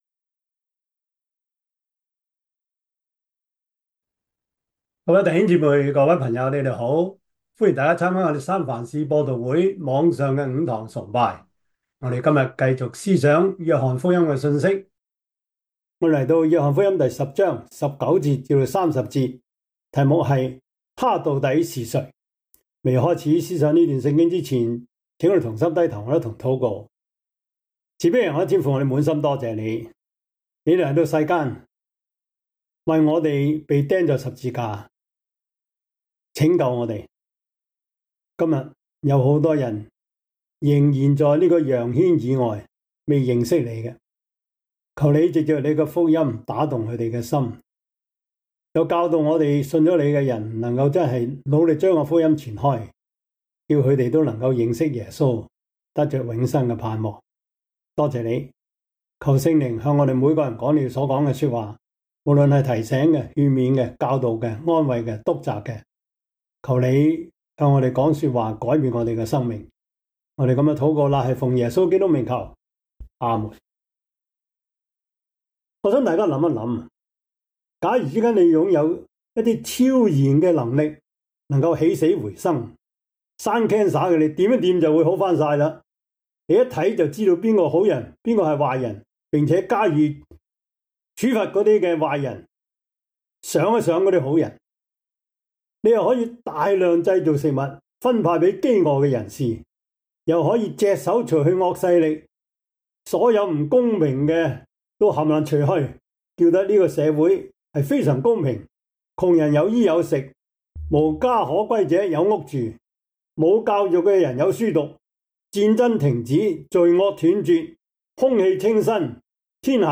約翰福音 10:14-18 Service Type: 主日崇拜 約翰福音 10:19-30 Chinese Union Version